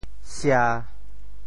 “斜”字用潮州话怎么说？
sia1.mp3